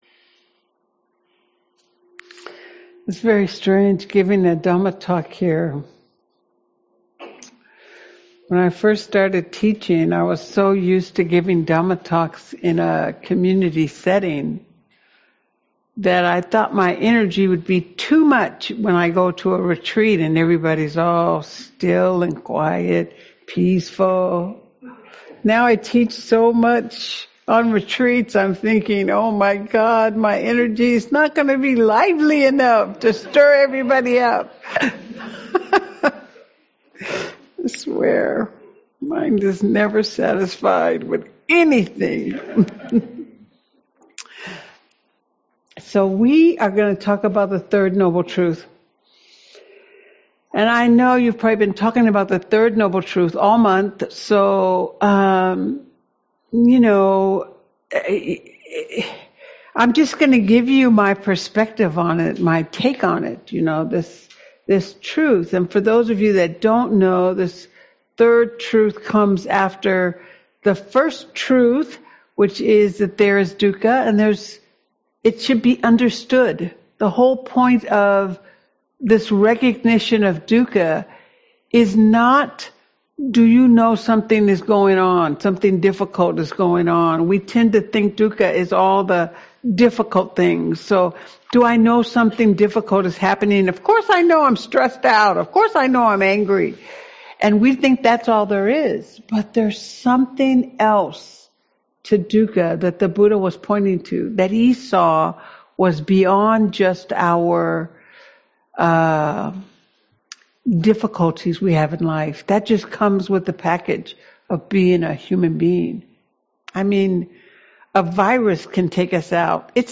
Audio recordings of Buddhist teachings and discussions with local and visiting teachers of the Dhamma.